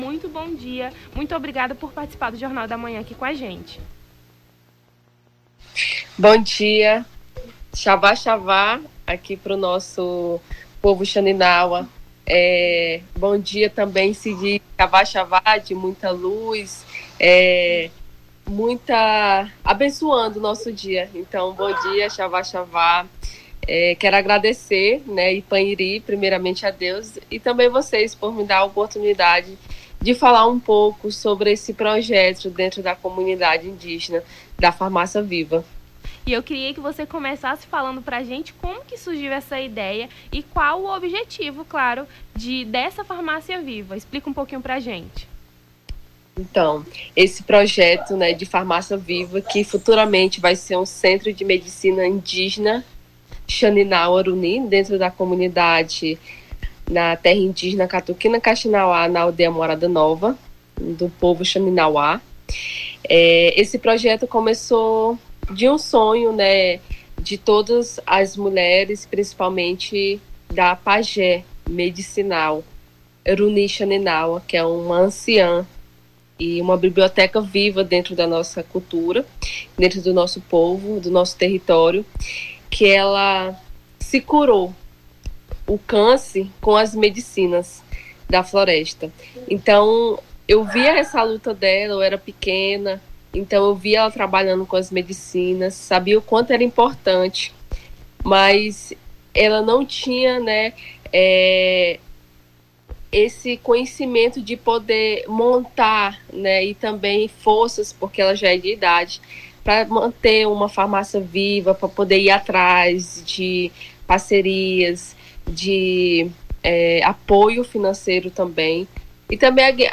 Nome do Artista - CENSURA - ENTREVISTA (FARMACIA VIVA) 06-11-23.mp3